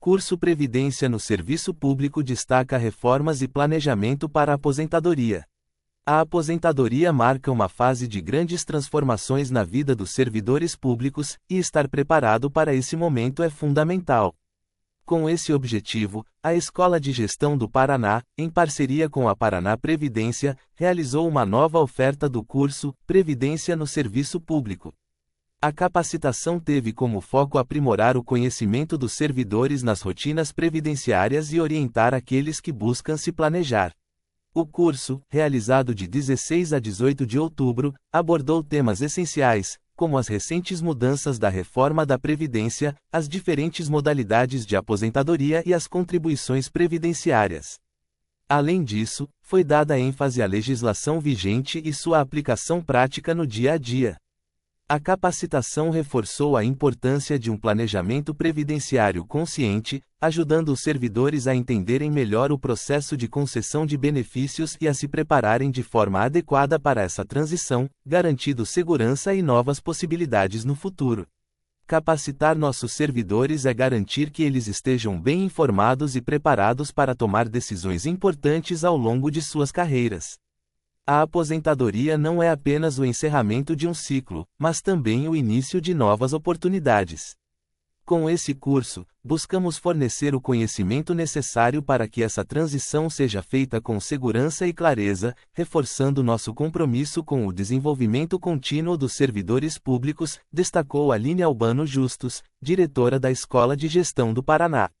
audionoticia_curso_previdencia.mp3